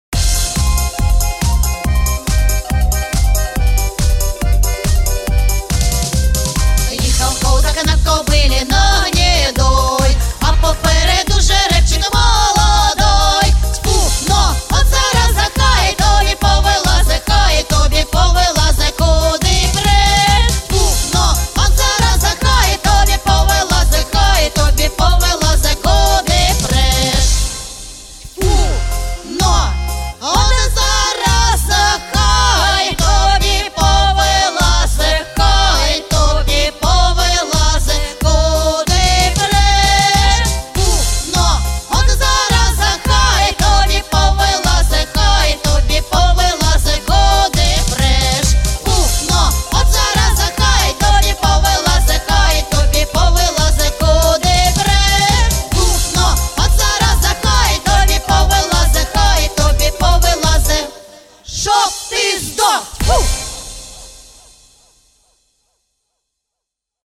Всі мінусовки жанру жартівлива
Плюсовий запис